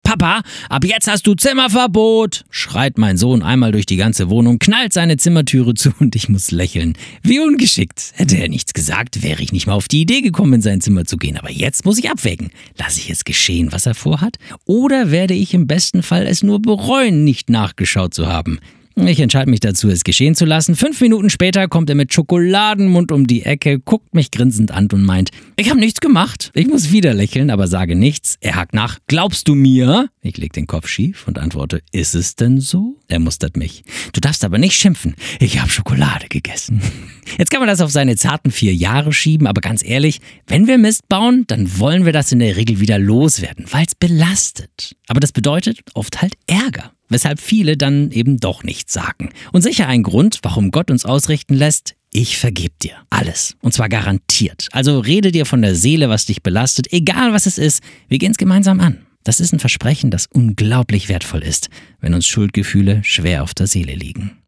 Startseite > andacht > Garantiert ohne Schimpfen!